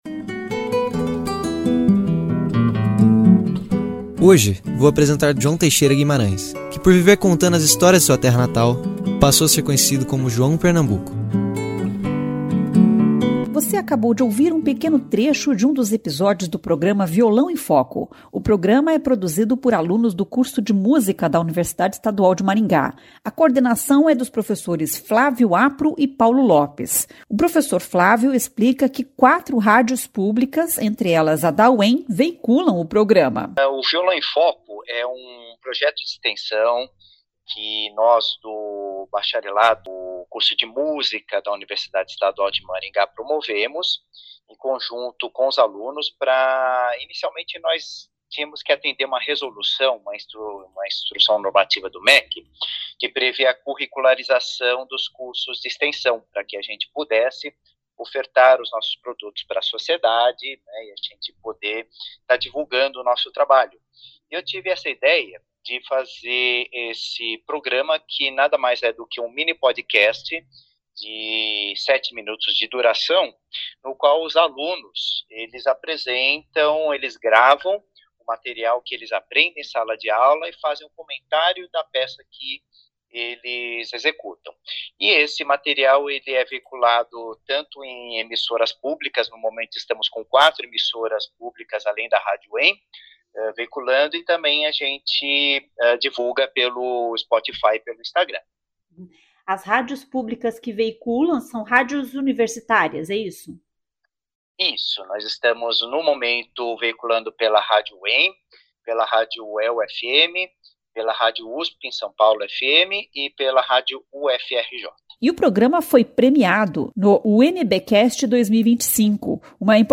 Você acabou de ouvir pequeno trecho de um dos episódios do programa Violão em Foco.